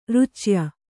♪ rucya